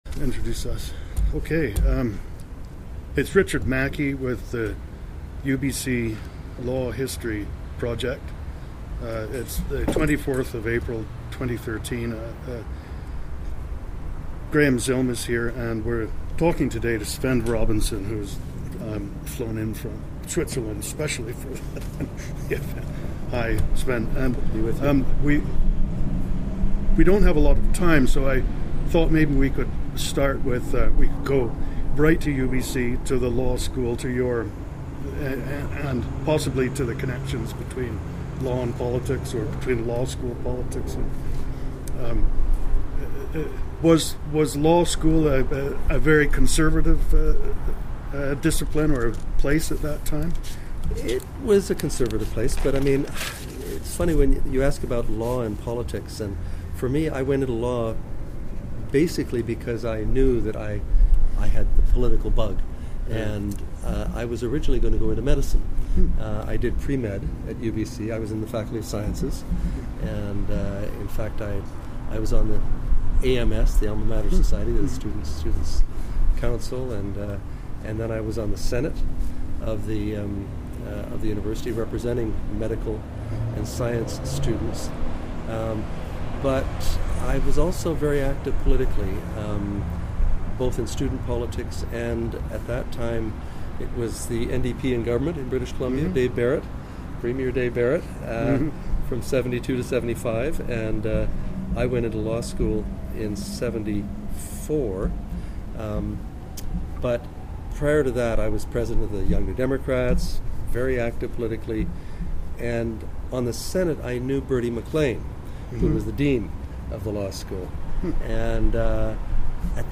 For more, listen to the Allard Law History Project interview with Svend Robinson.